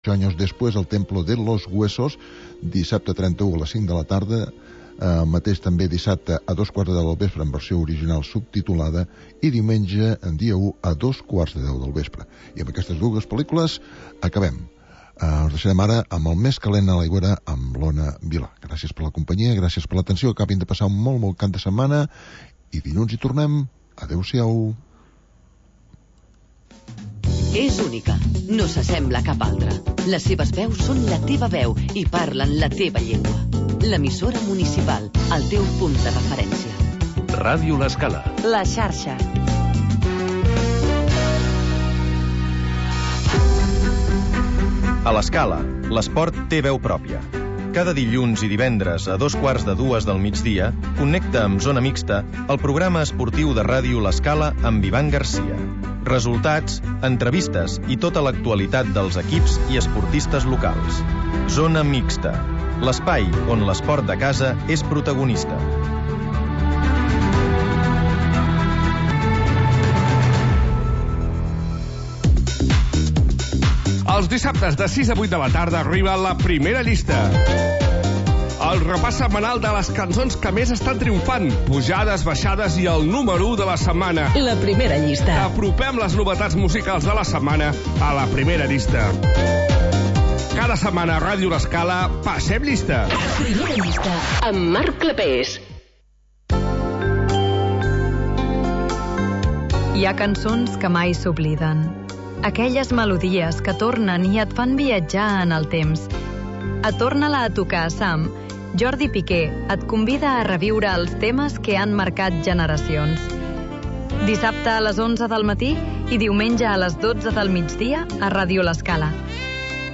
Magazín d'entreteniment per passar el migdia